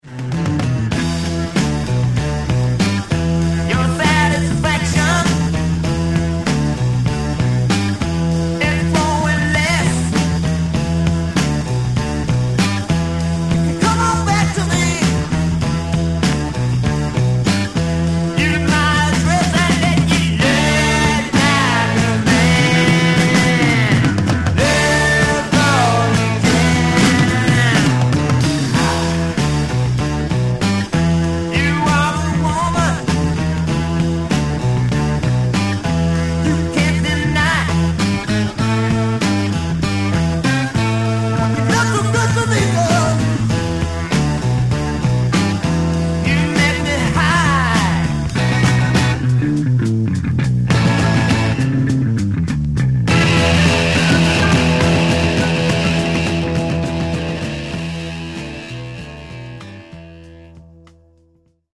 Genre: Hard Rock/Metal
electric guitar